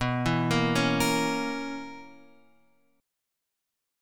B Major 7th Suspended 2nd Suspended 4th